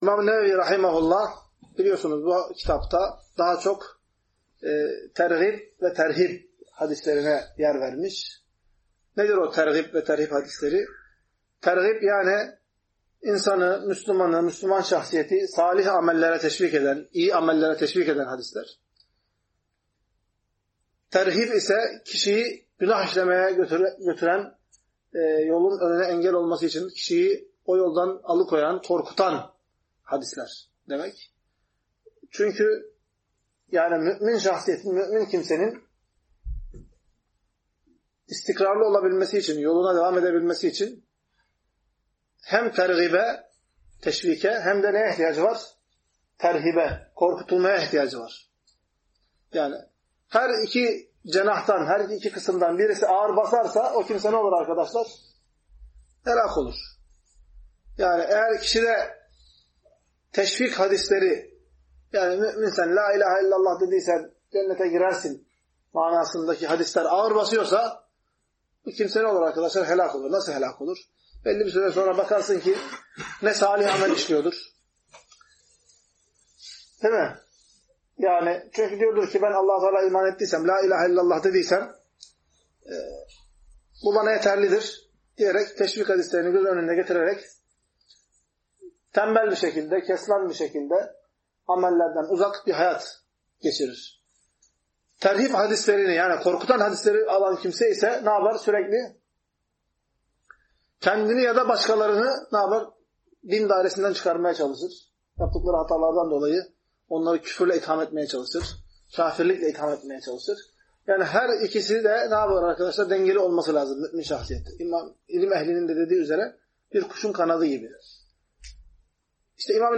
Ders - 20.